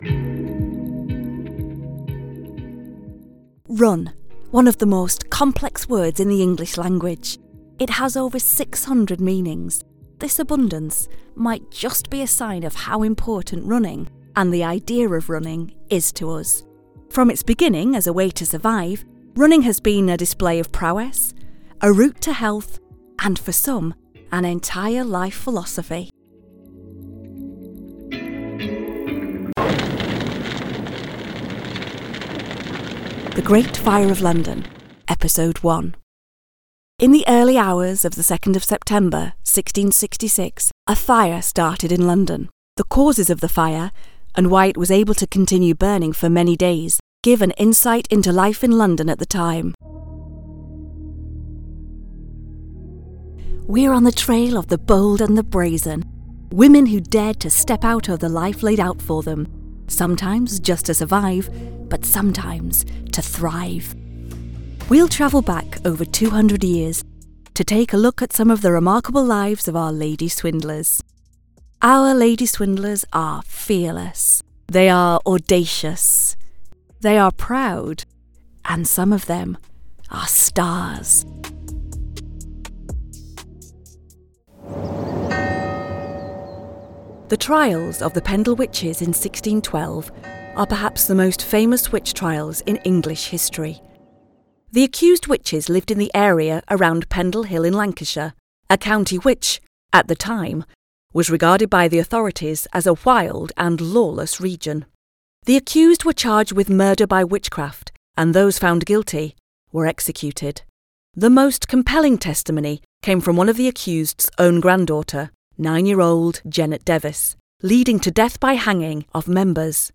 Voix off
Comédienne anglaise, beaucoup d'expérience théâtrale, des publicité, des voix off, de la formation.